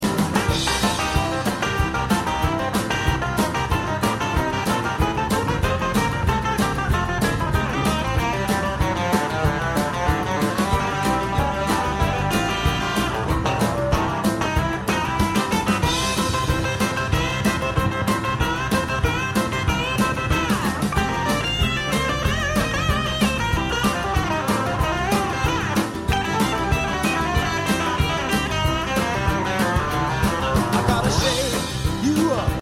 Guitar Styles Audio Samples
"Country"
clip-country.mp3